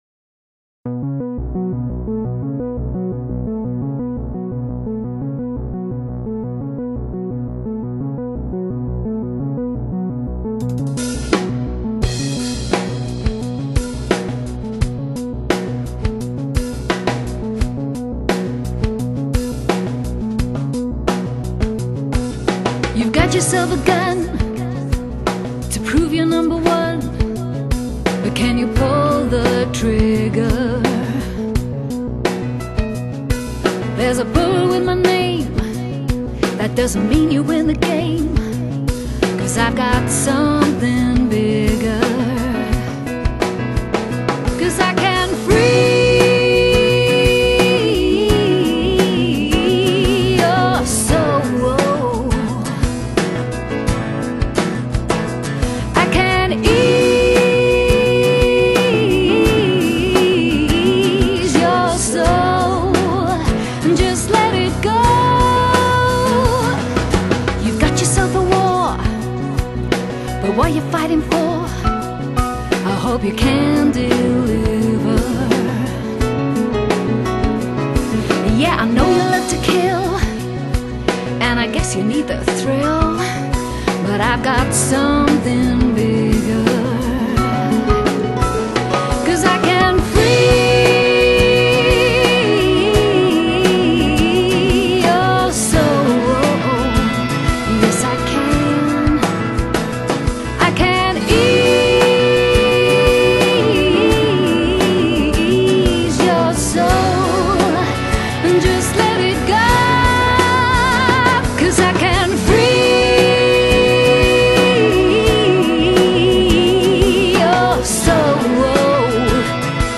Genre: Progressive Rock
sublime melodies and choruses